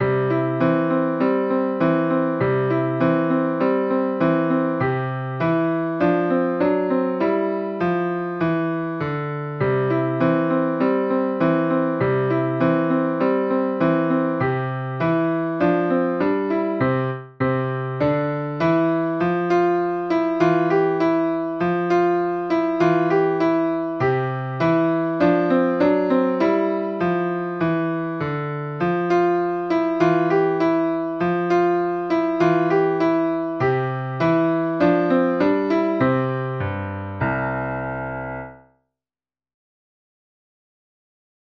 Jewish Folk Song
Piano Arrangement
C major ♩= 100 bpm
niggun_020b_mishenichnas_adar_piano_02.mp3